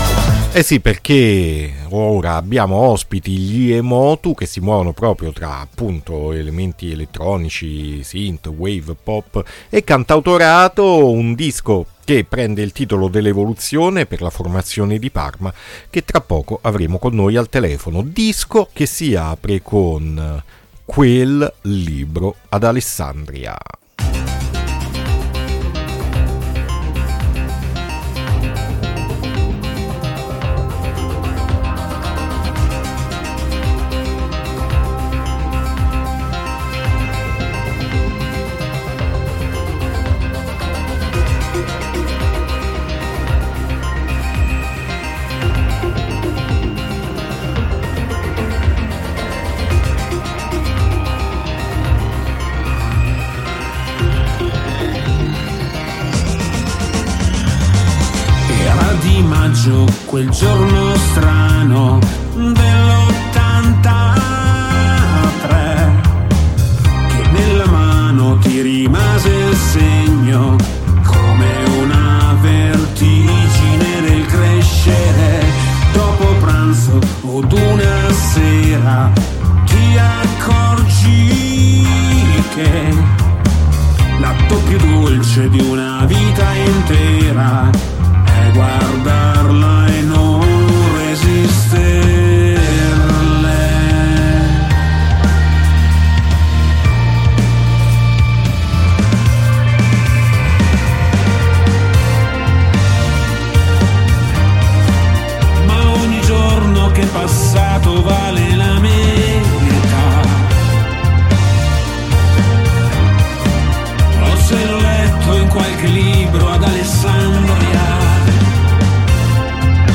INTERVISTA EMOTU A MERCOLEDI' MORNING 2-4-2025